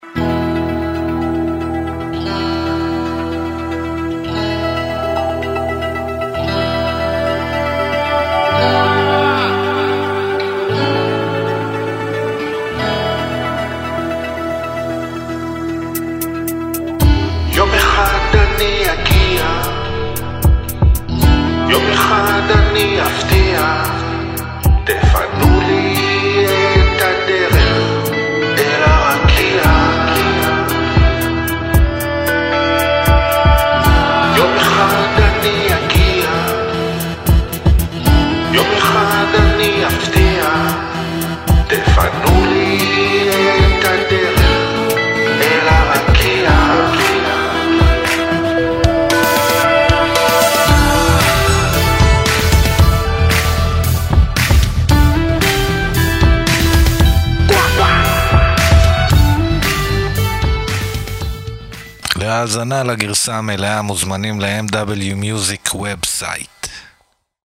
הרכב רוק־אינדי עברי חד, חצוף ועמוק